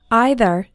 Both either and neither have two possible pronunciations:
Pronunciation #1 – EITHER